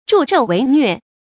注音：ㄓㄨˋ ㄓㄡˋ ㄨㄟˊ ㄋㄩㄝˋ
助紂為虐的讀法